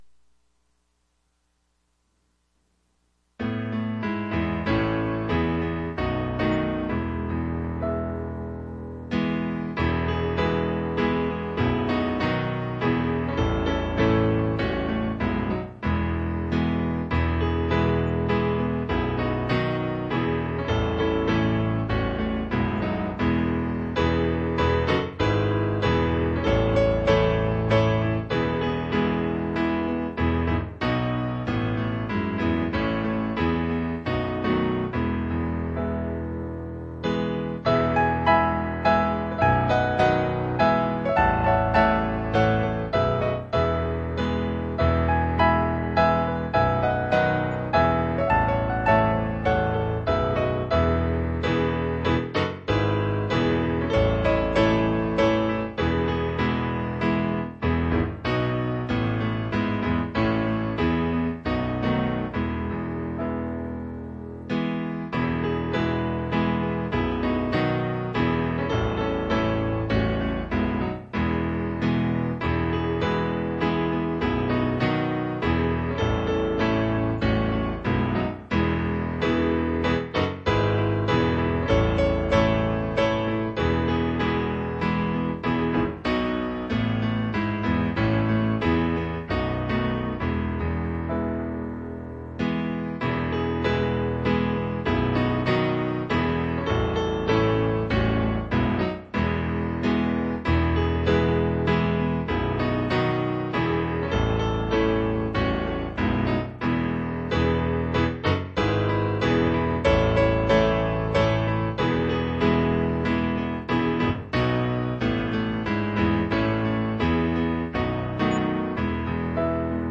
Bible Text: Ruth 3:1-14 | Preacher